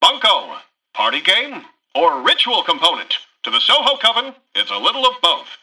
Newscaster_headline_19.mp3